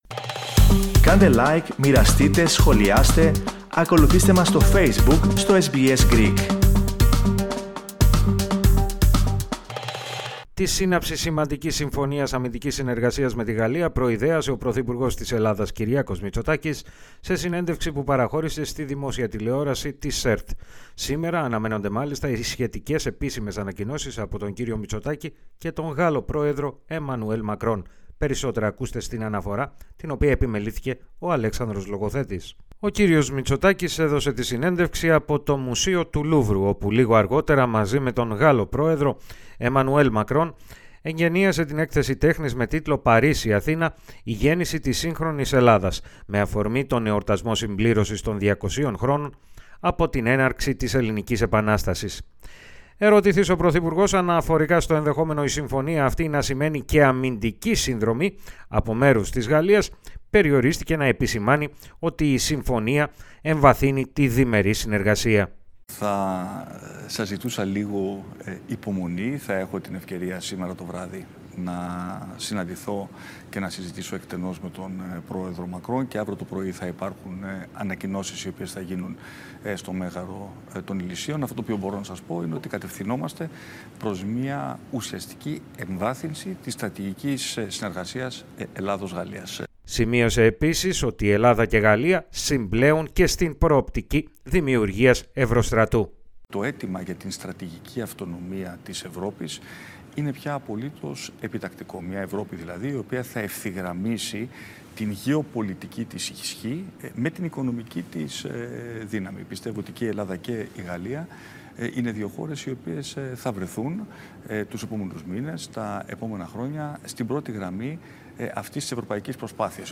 Περισσότερα, ακούμε στην αναφορά